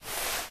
wall_slide_2.ogg